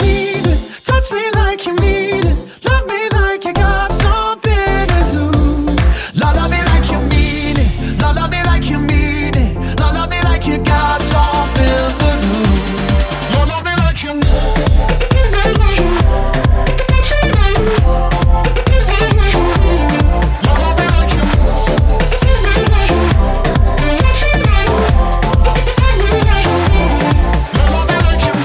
Genere: pop, deep, club,, remix